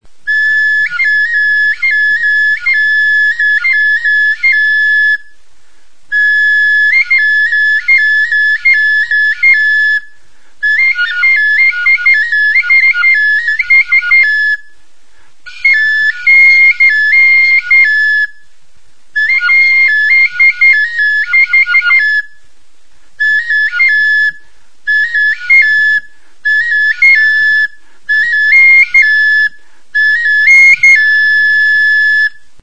Aerophones -> Flutes -> Fipple flutes (two-handed) + kena
TXILIBITUA
Kanaberazko hiru zuloko bi eskuko flauta zuzena da.